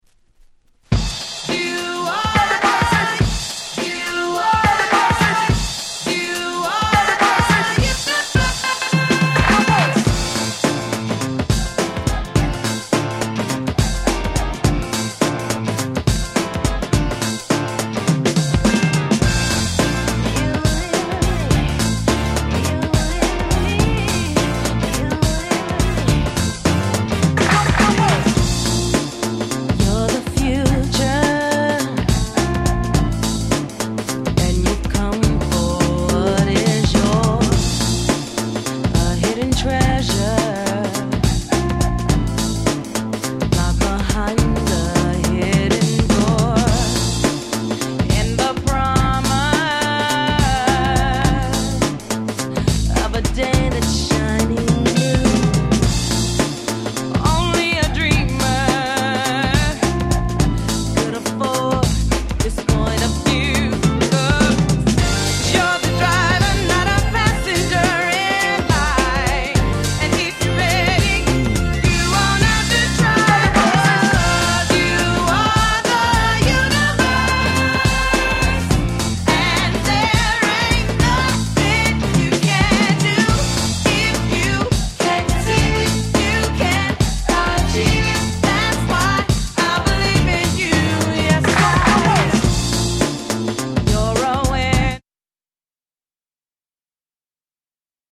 ClubでPlayするには寧ろOriginalよりもコッチの方が破壊力は高いです。